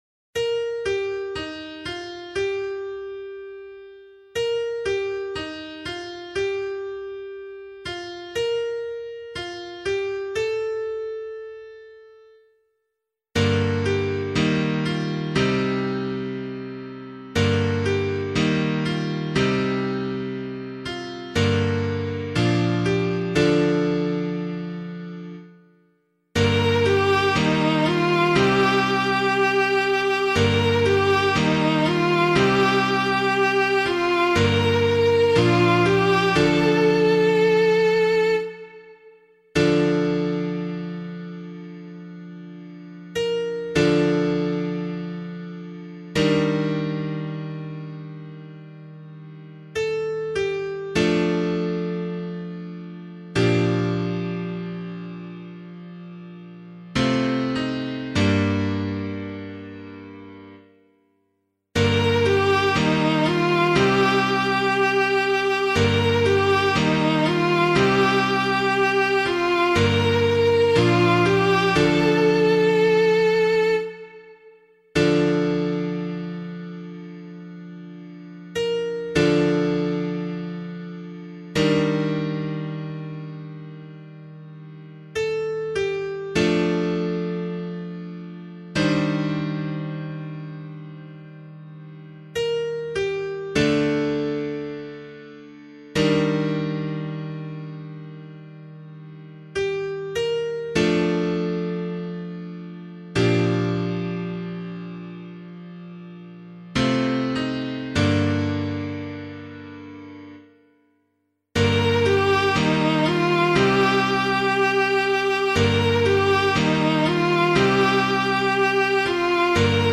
001 Advent 1 Psalm B [APC - LiturgyShare + Meinrad 3] - piano.mp3